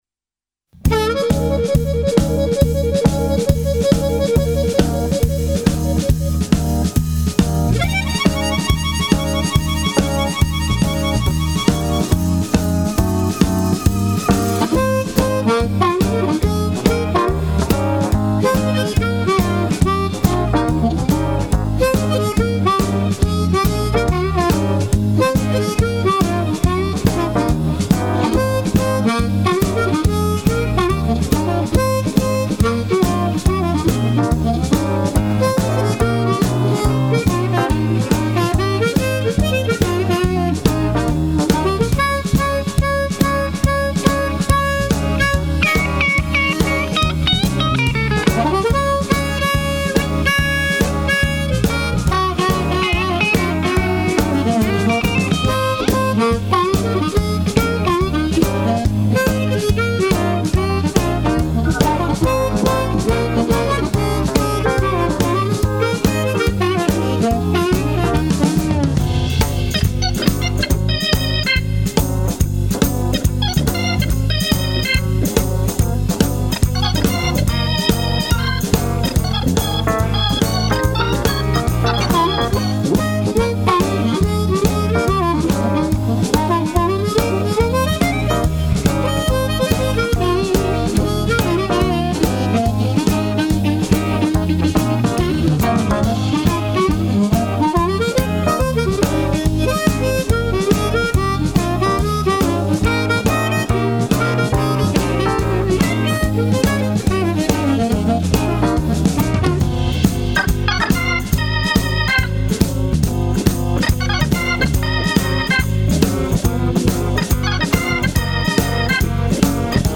soirée blues et soul aux multiples influences